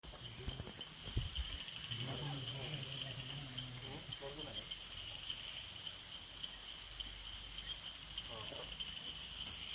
Scientific Name: Raorchestes Kadalarensis Common Name: Kadalar Bush Frog